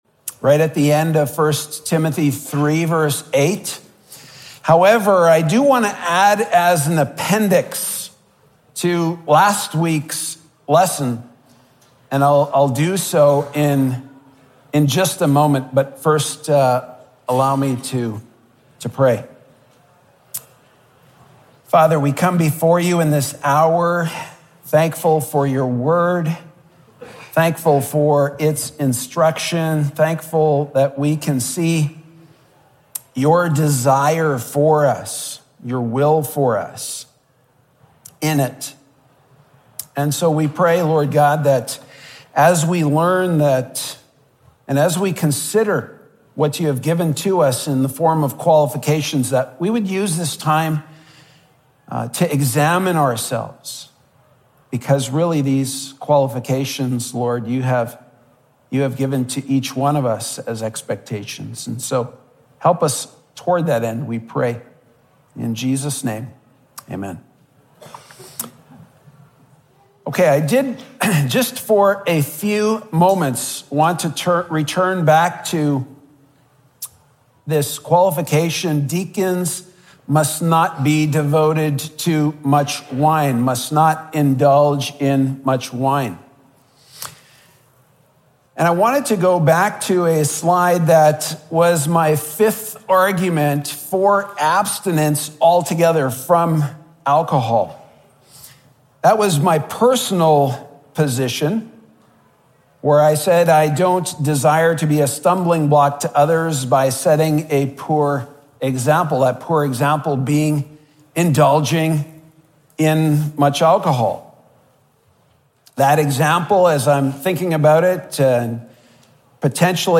Category: Sunday School